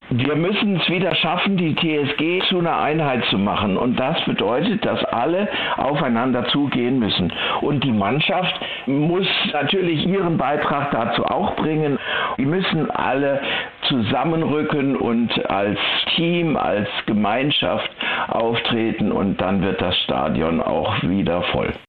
Interview mit langjährigem TSG-Hoffenheim-Fan